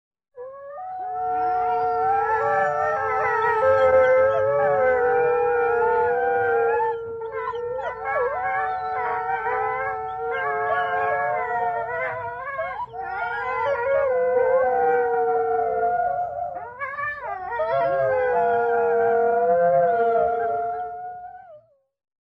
Звуки воя волка
Волчья стая скулит и воет